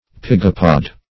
Search Result for " pygopod" : The Collaborative International Dictionary of English v.0.48: Pygopod \Py"go*pod\, n. [Gr. pygh` rump + -pod.]